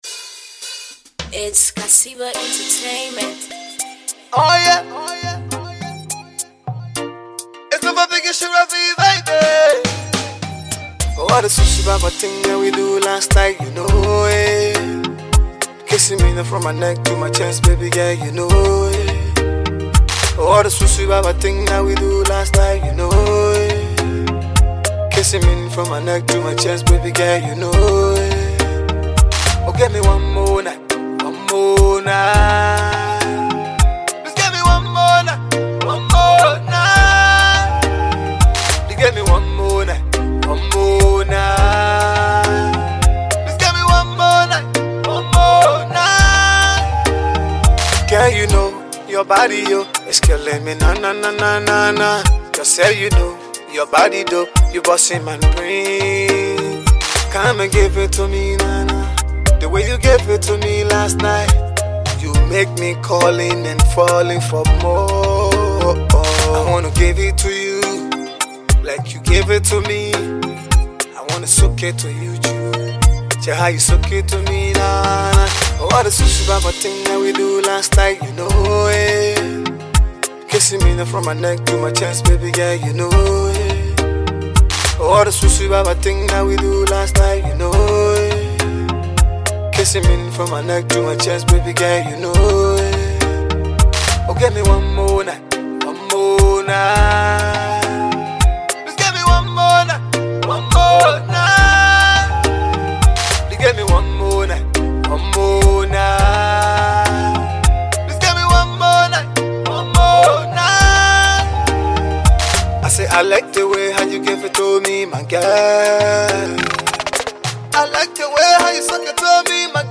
/ Afro-Pop / By